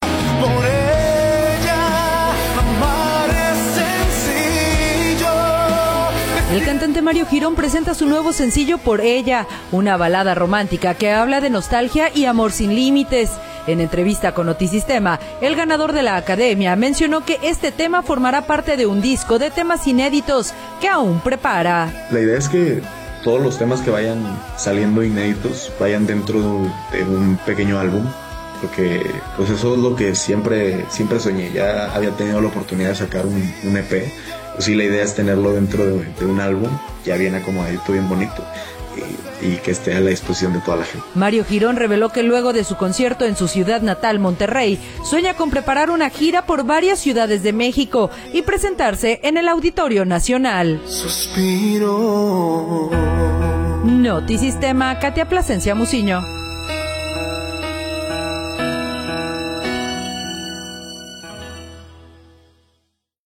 En entrevista con Notisistema, el ganador de La Academia mencionó que este tema formará parte de un disco de temas inéditos […]